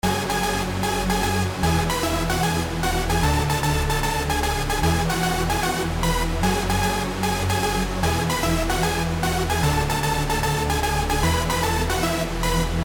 Tag: 黄铜 战斗 20世纪60年代 蝙蝠侠